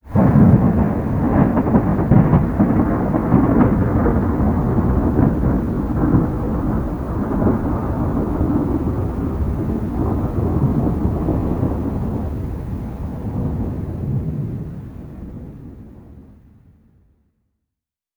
thunder_far.wav